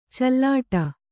♪ callāṭa